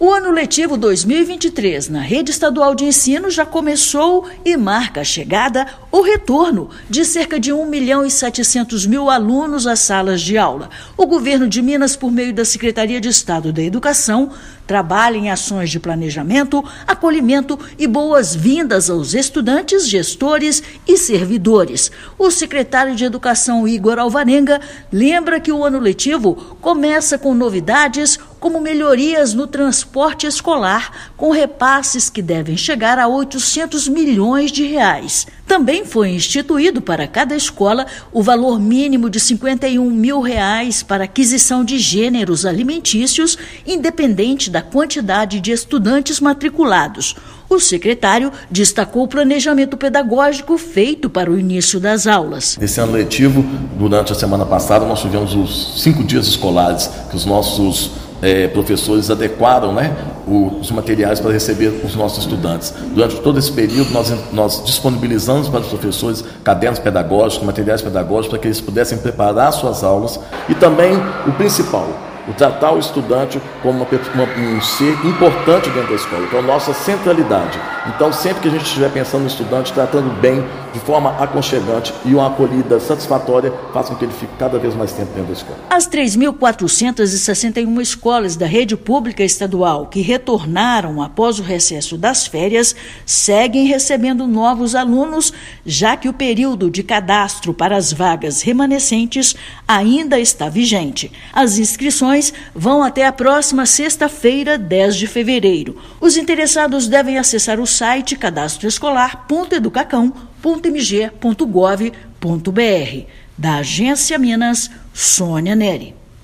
O ano letivo de 2023 na Rede Estadual de Ensino de Minas Gerais marca a chegada ou o retorno de 1,7 milhão de estudantes às salas de aulas. Ouça matéria de rádio.